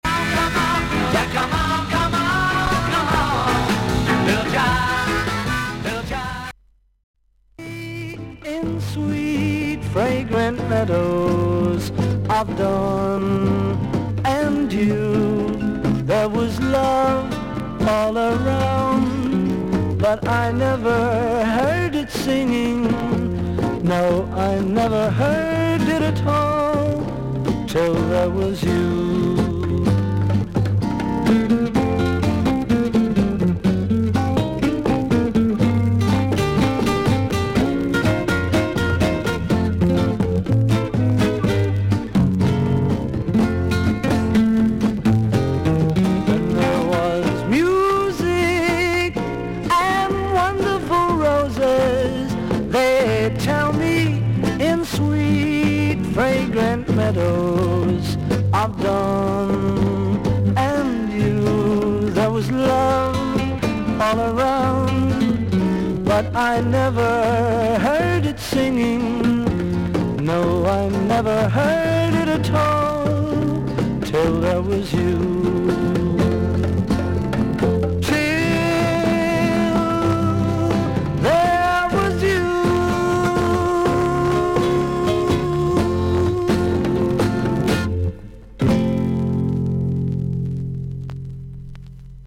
A5後半に5mmほどの横キズあり、2回パチノイズあり。
ほかはVG+〜VG++:少々軽いパチノイズの箇所あり。少々サーフィス・ノイズあり。クリアな音です。